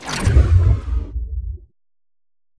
flash_shield.ogg.bak